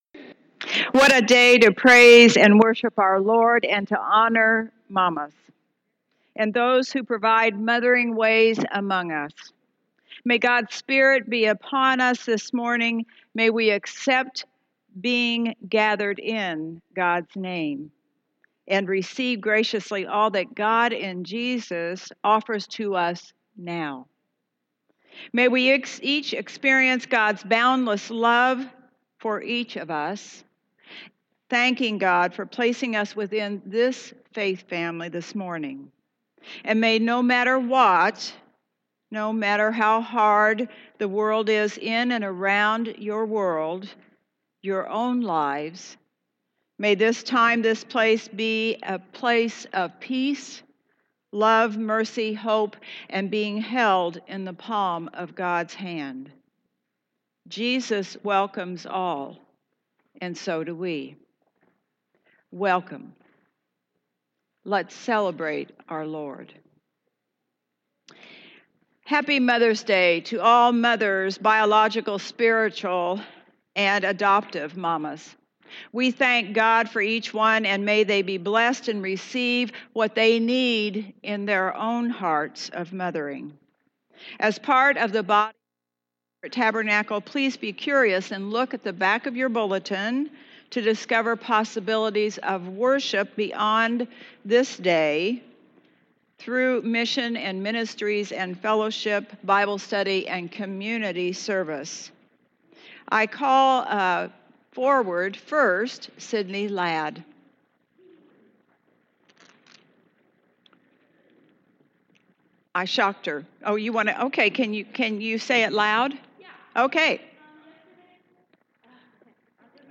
Tabernacle Christian Church Sunday Service Audio